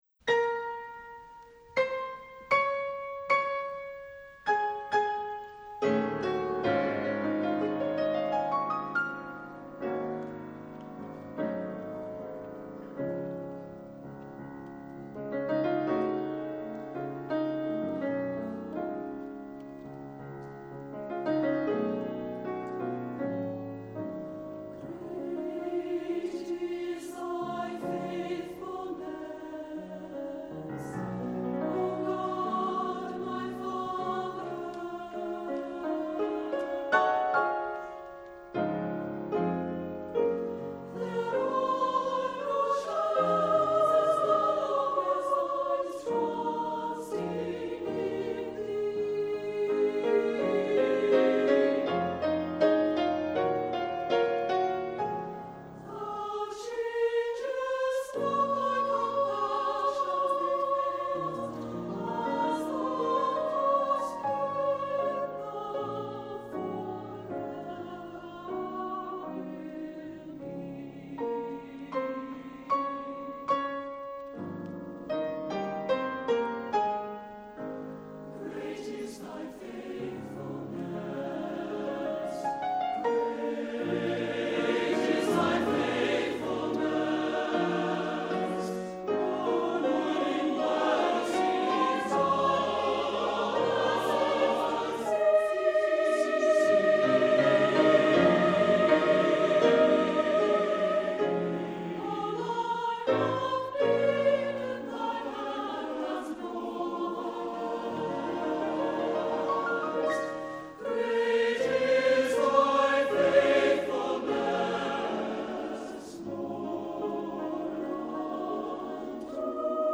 Voicing: SSATB; Adults with children